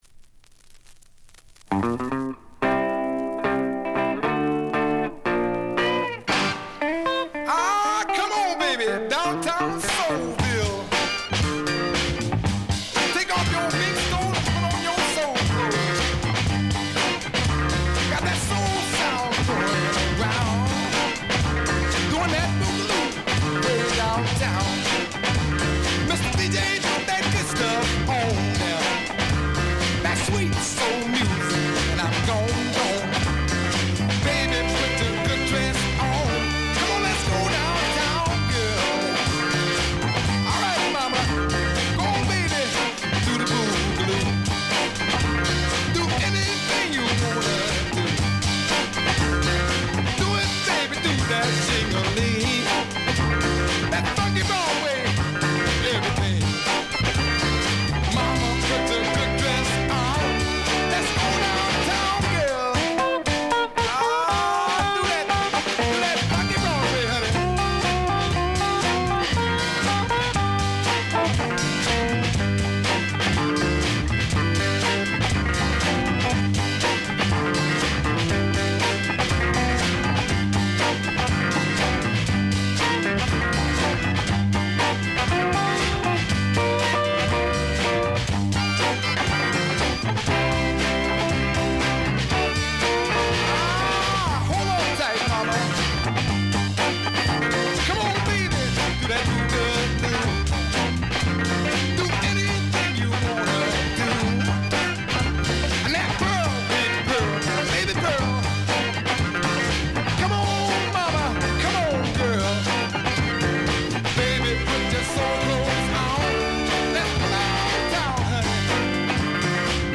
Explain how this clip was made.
Music behind DJ